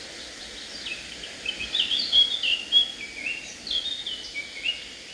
Birds -> Warblers ->
Blackcap, Sylvia atricapilla
StatusSinging male in breeding season